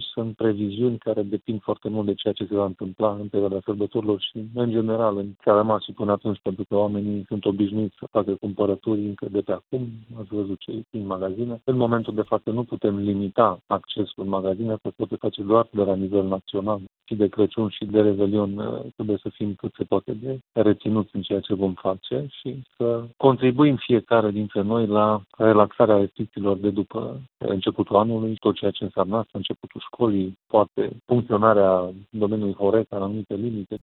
Comportamentul responsabil, încă de acum, este esențial pentru ridicarea unor restricții, a adăugat prefectul Mircea Abrudean: